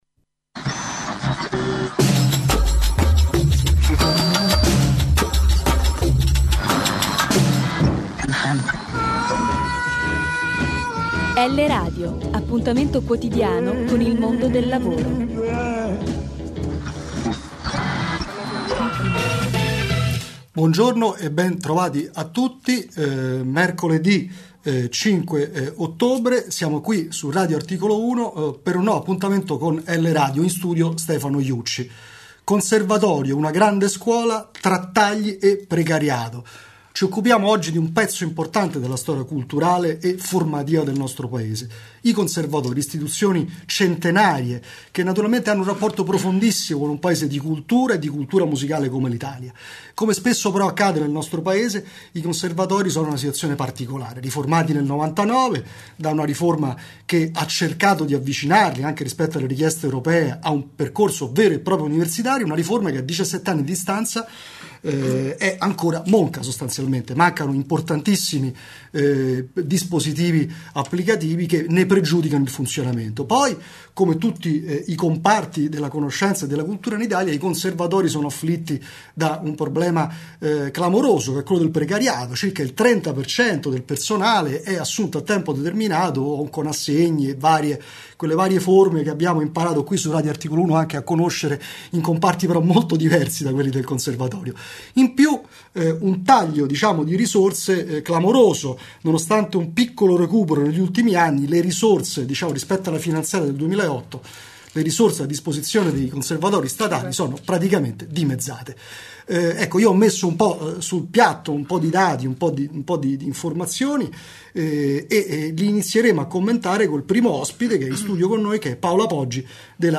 Da Radio Articolo 1, trasmissione del 05.10.2016: